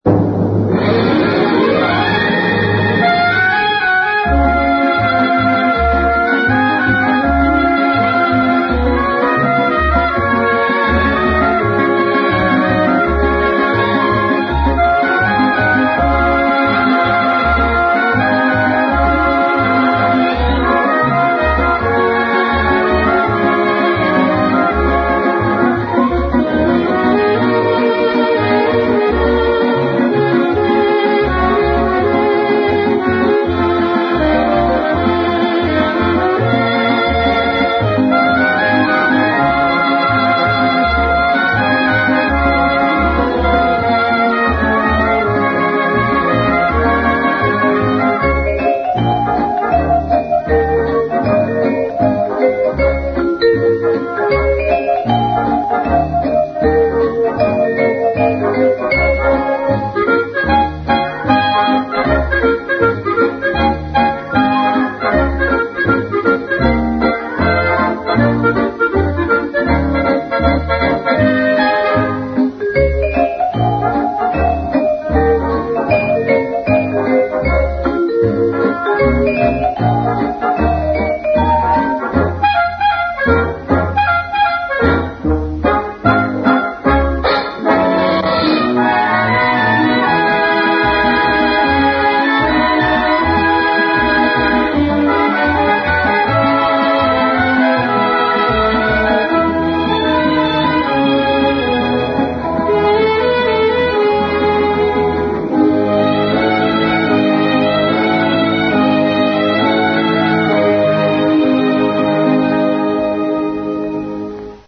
Générique mp3 / Générique ram